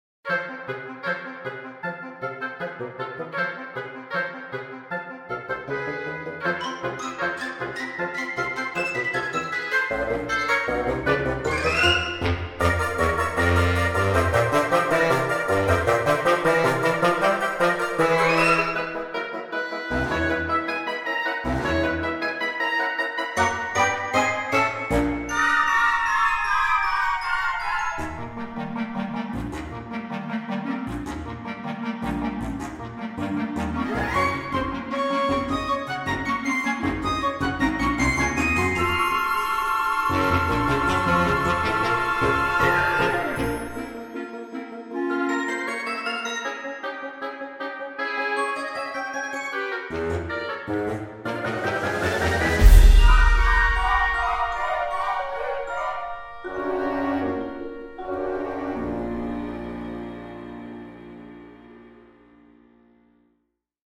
巨大的打击乐声音，包括：太鼓，汤姆合奏，军鼓等。
更多木质打击乐，例如：马林巴、电颤琴、克罗塔莱斯、钢片琴等。
木管乐器的神秘音色
长笛1和2
双簧管1&2
大打击乐（太鼓，汤姆合奏，格兰卡萨，谭谭，军鼓，皮亚蒂，吊钹）